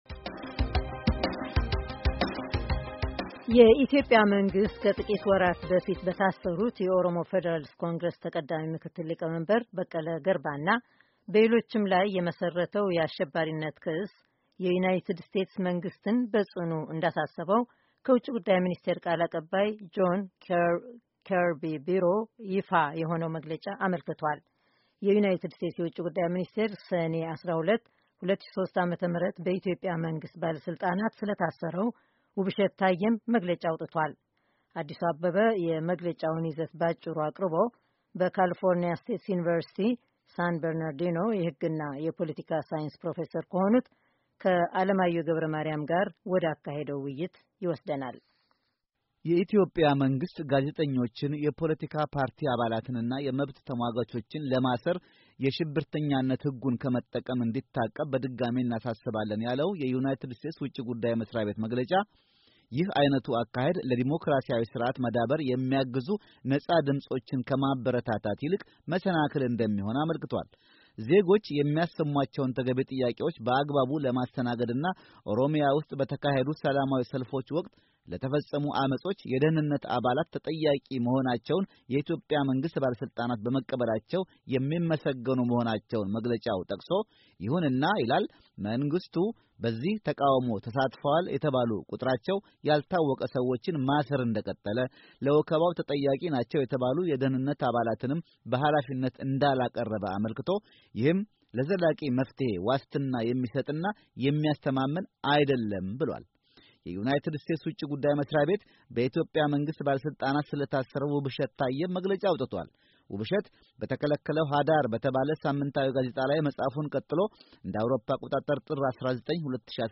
ውይይት በኢትዮጵያ መንግሥት የጸረ ሽብርተኛነት ህግ ላይ